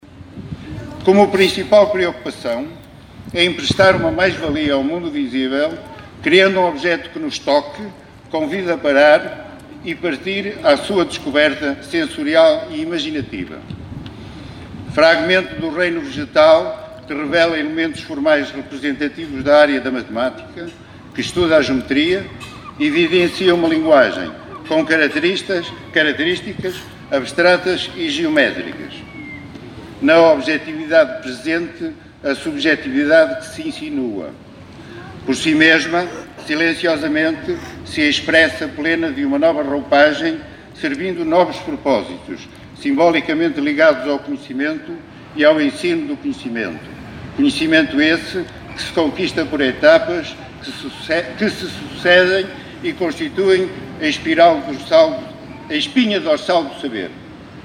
Foi inaugurada ontem, no Largo Sidínio Pais em Caminha, a escultura SCHOLA, um marco que presta homenagem à comunidade escolar do concelho, passada e presente.